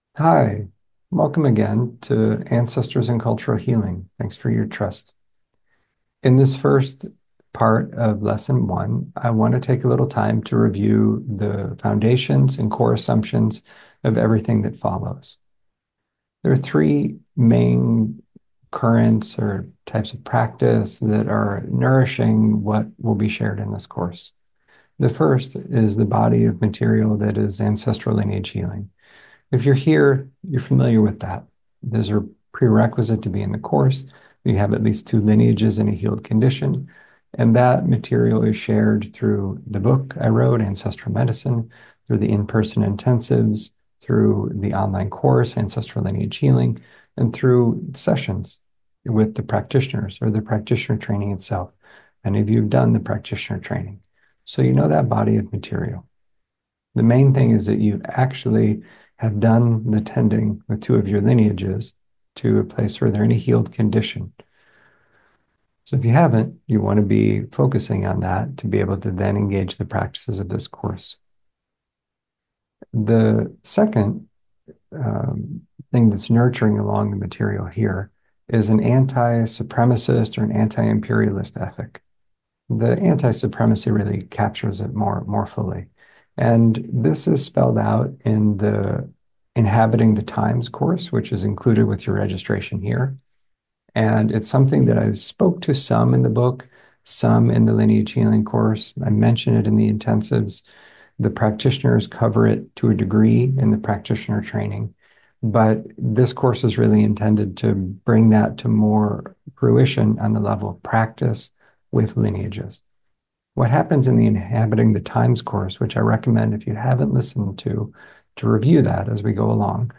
A teaching on Earth-subversive kindness
Includes guided practice, Q&A, and a recorded replay.